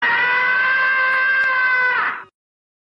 Play, download and share tom scr34ming original sound button!!!!
tom-scr34ming.mp3